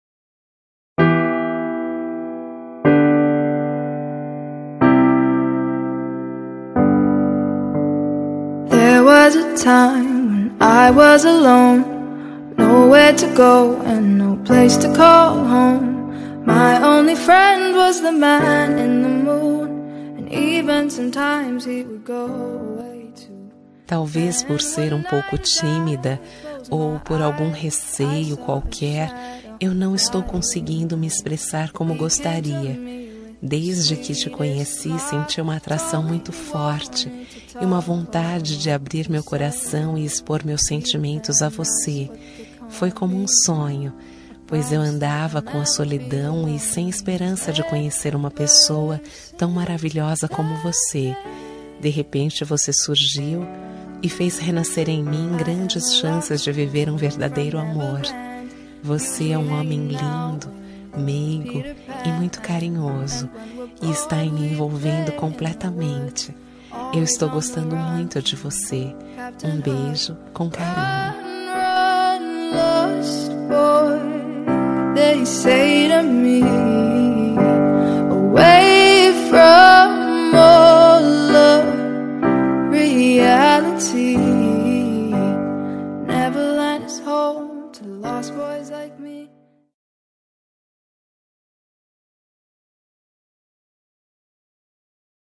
Telemensagem Paquera – Voz Feminina – Cód: 051589